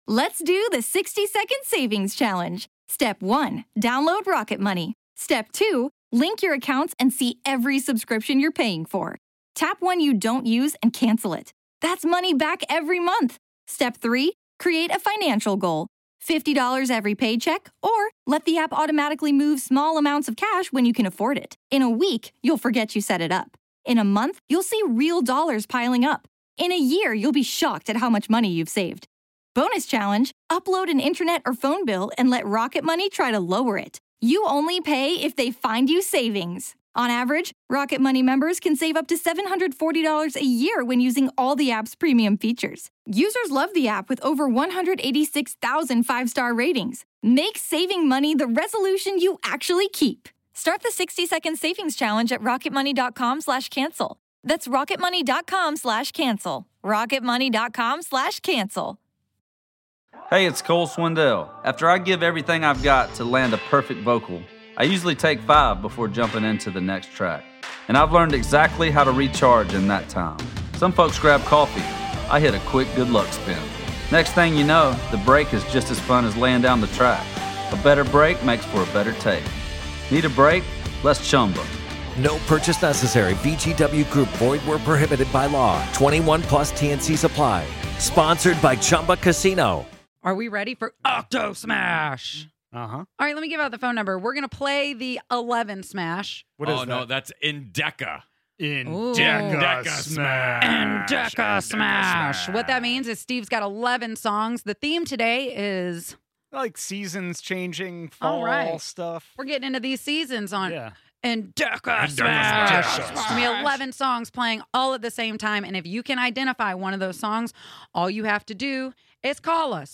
Can you hear one song, two songs, or more?!